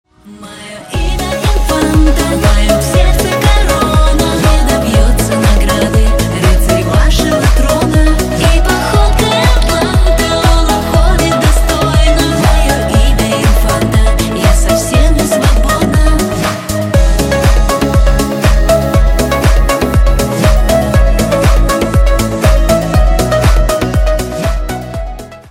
• Качество: 128, Stereo
попса